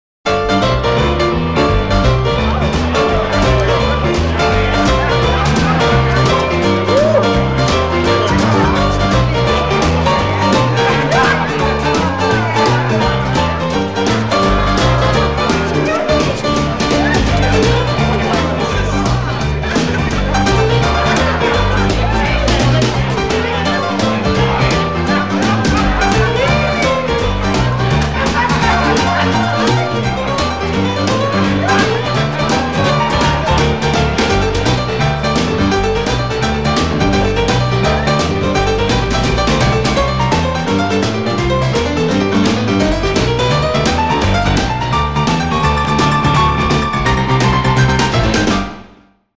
без слов
пианино
РОК-Н-РОЛЛ
танцевальная музыка